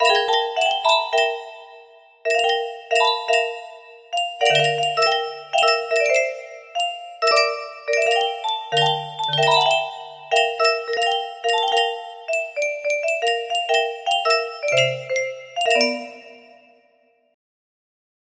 2 music box melody